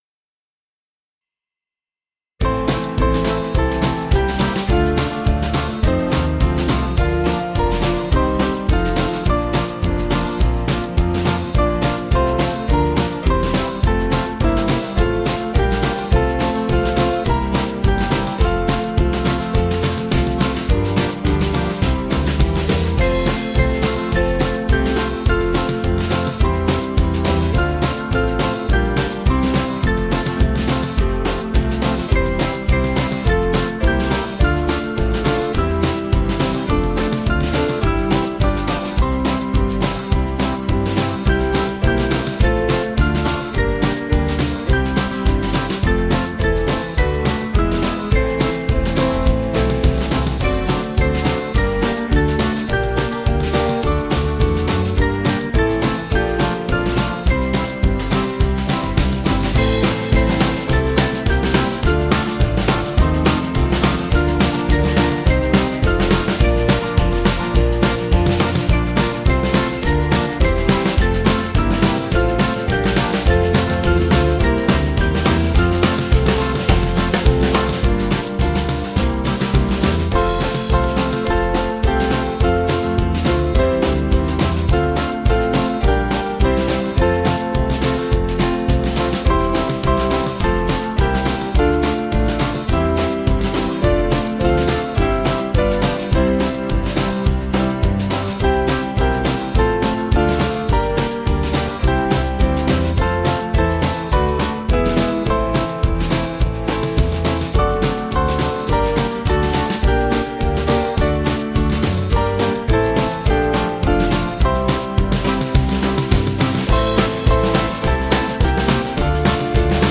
Small Band